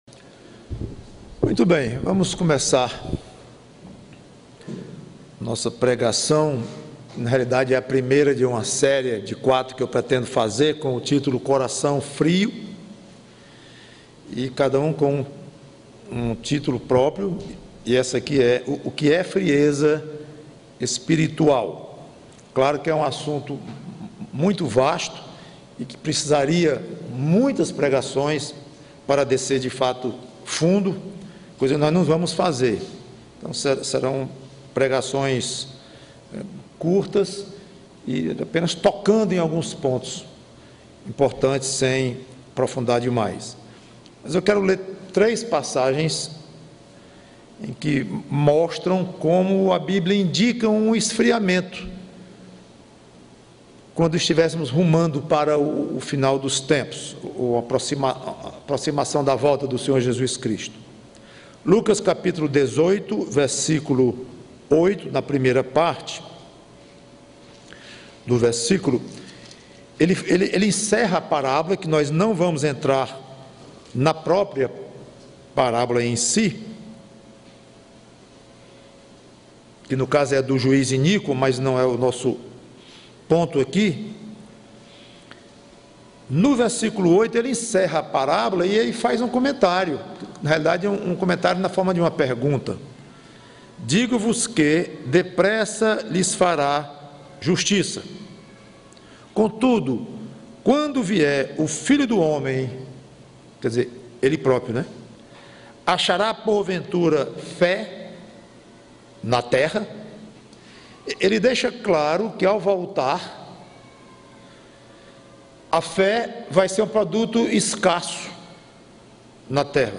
PREGAÇÃO